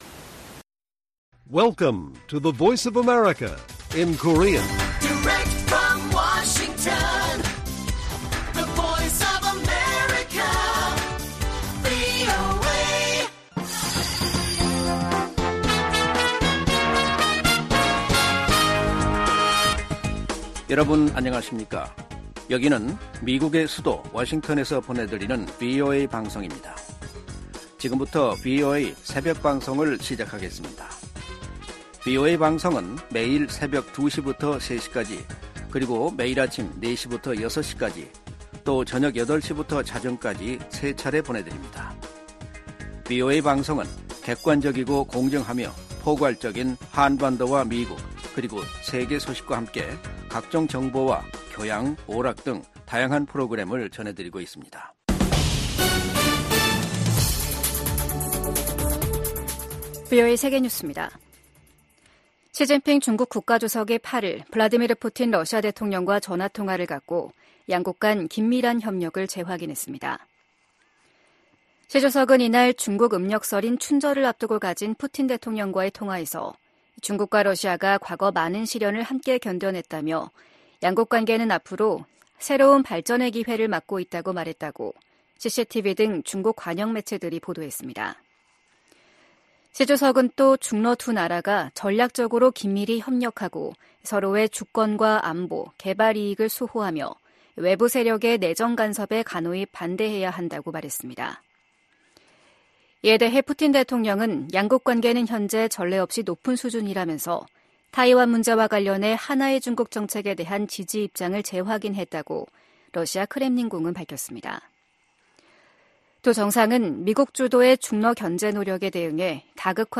VOA 한국어 '출발 뉴스 쇼', 2024년 2월 9일 방송입니다. 윤석열 한국 대통령은 북한 정권이 비이성적인 정치세력임으로 이에 대비, 더 튼튼한 안보를 구축해야 한다고 강조했습니다. 미국 정부는 북한-러시아 군사협력이 강화되면서 북한 대량살상무기 개발을 진전시킬 것이라고 우려했습니다.